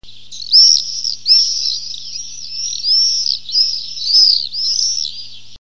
Martinet noir
Apus apus
martinet.mp3